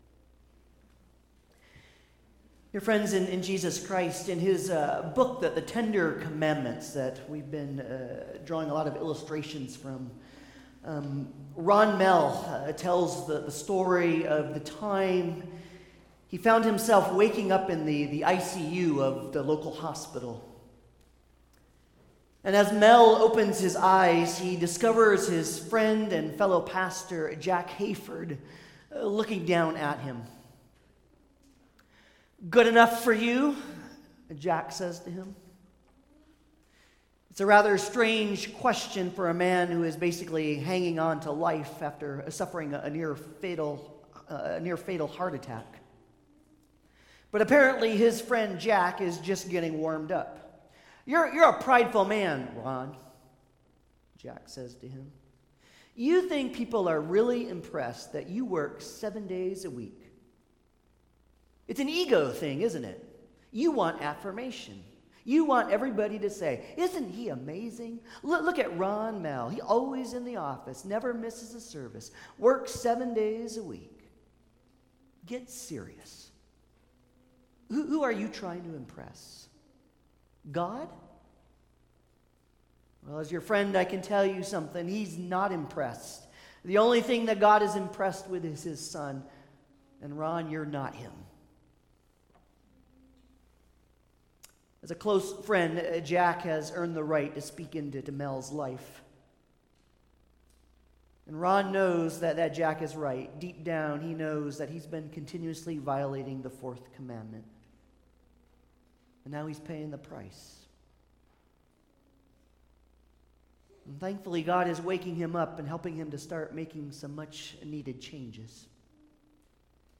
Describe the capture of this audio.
Commandments Passage: Exodus 20:8-11, Mark 2:23-27, Hebrews 4:9-11 Service Type: Sunday Service